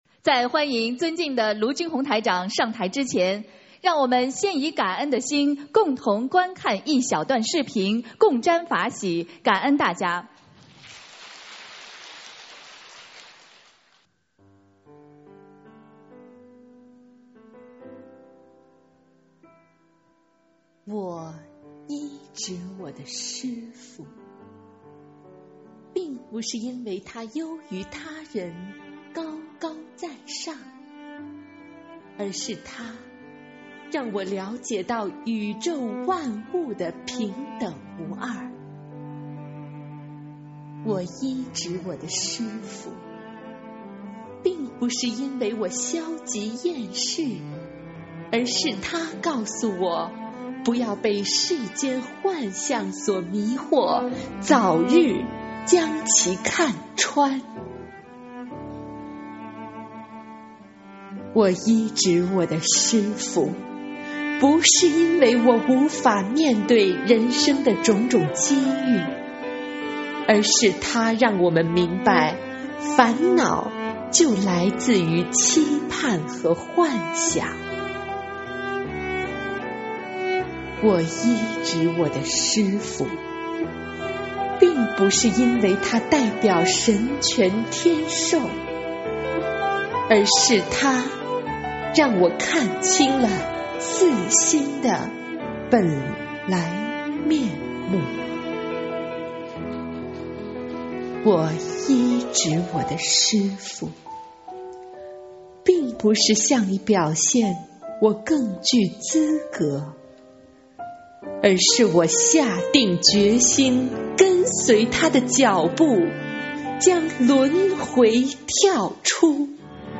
【2017澳大利亚·墨尔本】11月11日 佛友见面会 文字+音频 - 2017法会合集 (全) 慈悲妙音